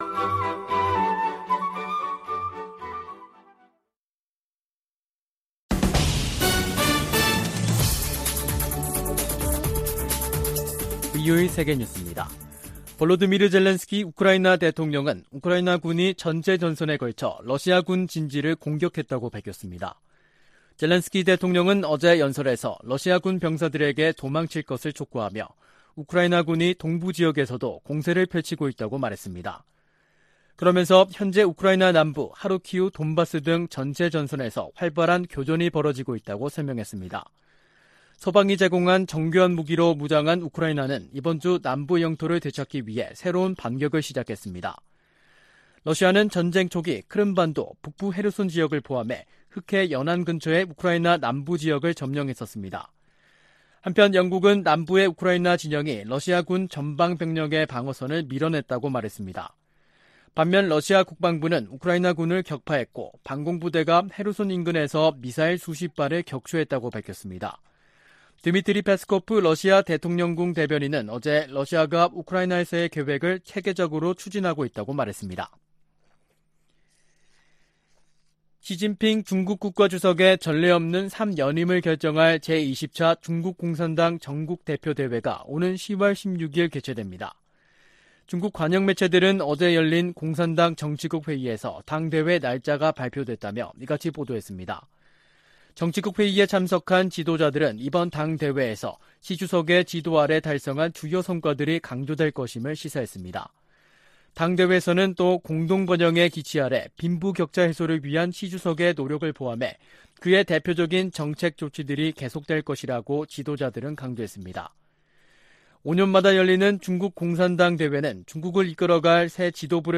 VOA 한국어 간판 뉴스 프로그램 '뉴스 투데이', 2022년 8월 31일 2부 방송입니다. 북한이 7차 핵실험을 할 경우 미국은 한국 등 역내 동맹국의 안보를 위해 추가 행동에 나설 것이라고 백악관이 시사했습니다. 북한이 핵무기 능력 고도화에 따라 생화학무기와 사이버 역량을 공격적으로 활용할 가능성이 커졌다는 분석이 나왔습니다. 북한이 인도의 민간단체에 식량 지원을 요청한 사실은 식량 사정의 심각성을 반영하는 것이라는 분석이 나오고 있습니다.